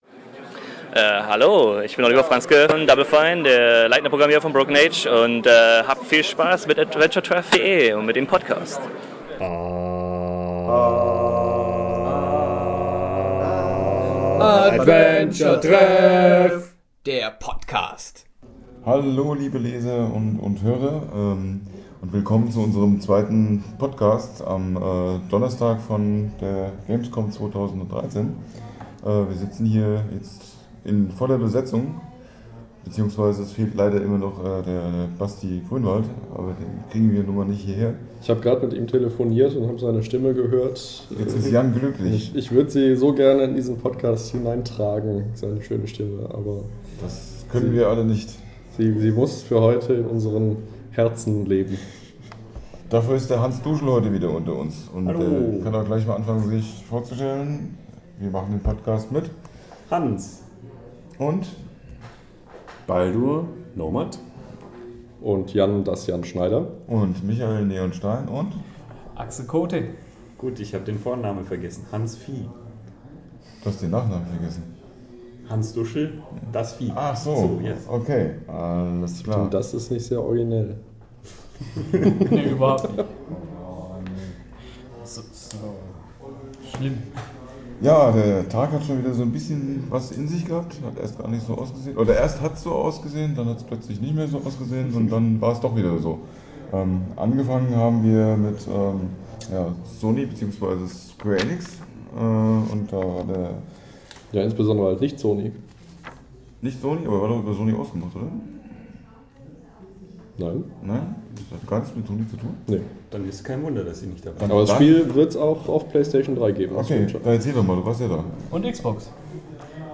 Um mich mal als Beteiligter zu äußern: Klar, man könnte den Podcast sicherlich noch etwas frischer, lockerer, lustiger, intelligenter und was weiß ich rüberbringen.
grade bei jungen leuten hab ich mir erhofft, dass es nicht so wie ein bericht von der tagesschau daher kommt.